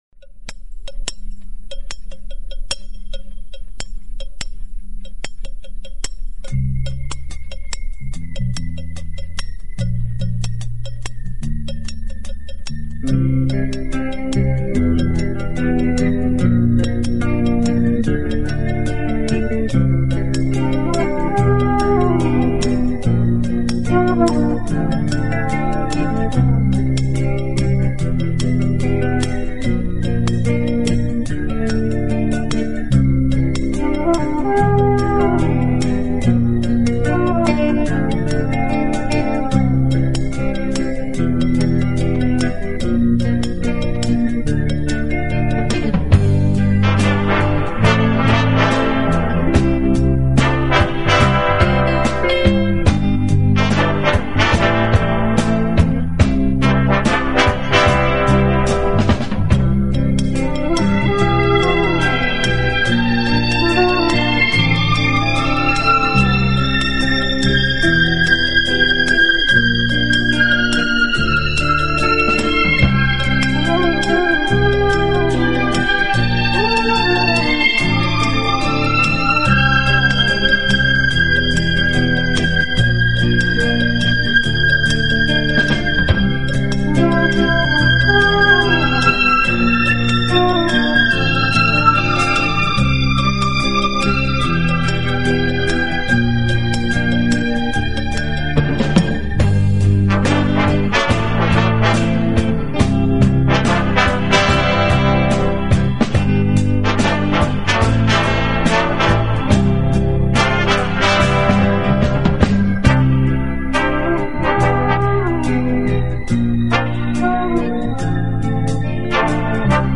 顶级轻音乐